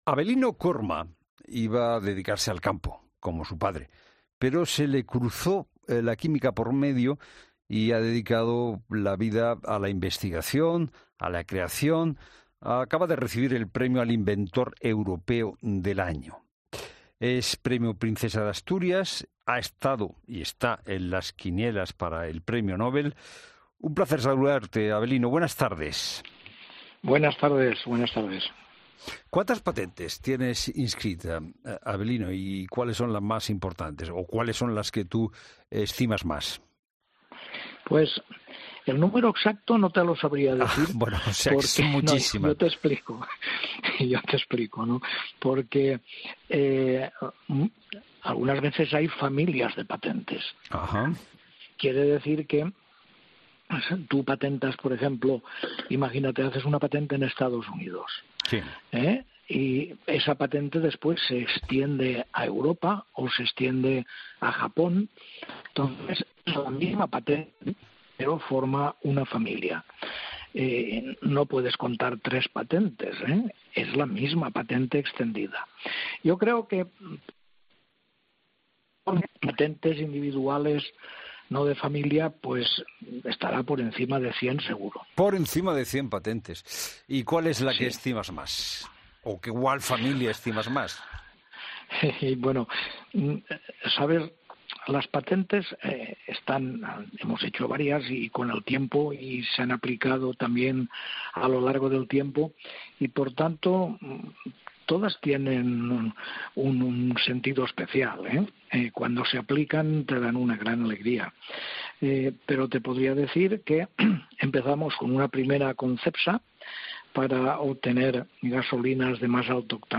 En 'La Tarde', Fernando de Haro ha repasado su trayectoria y le ha preguntado qué se necesita para ser un buen investigador.